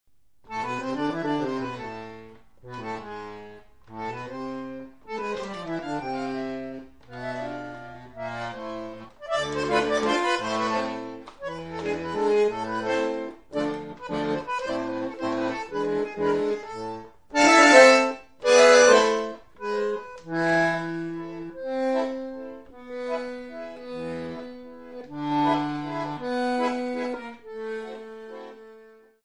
Bandoneon
Vals